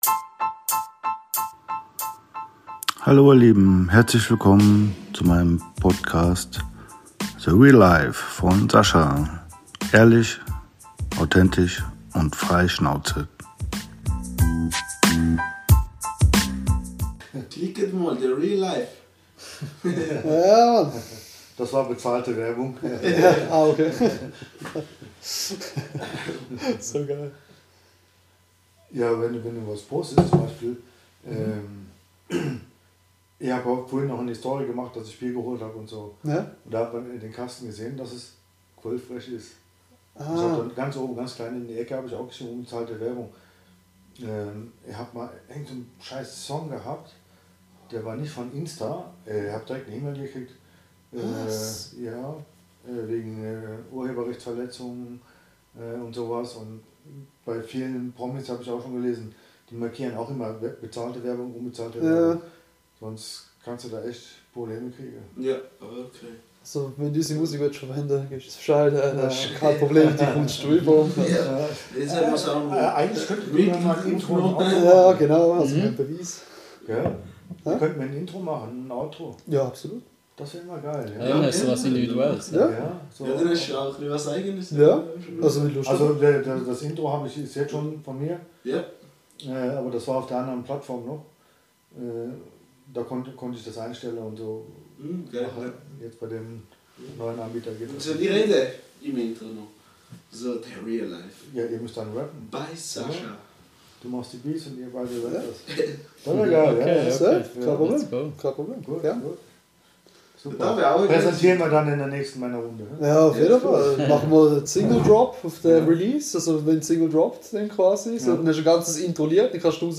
weiter geht es mit unserer angeregten Unterhaltung zum Thema Fische, angeln, und so typische Männer Sachen